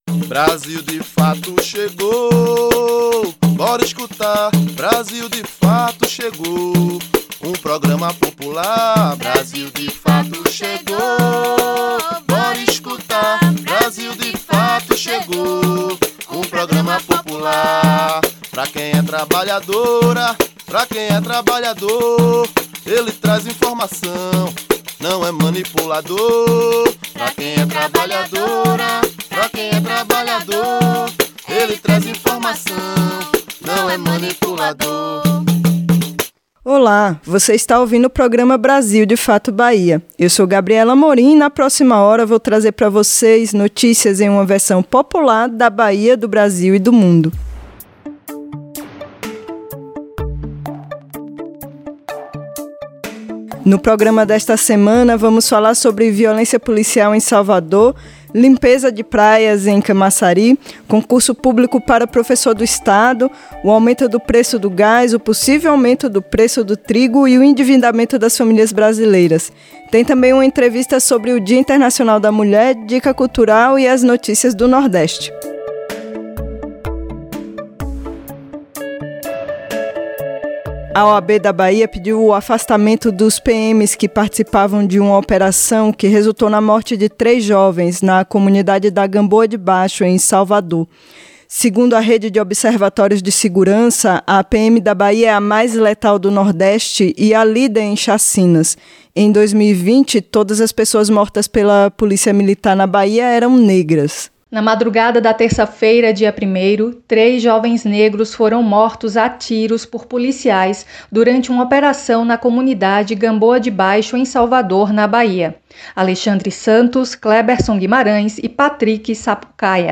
RÁDIO
Programa de rádio semanal vai ao ar todo domingo, das 8h às 9h, na Rádio Juazeiro 1190 AM.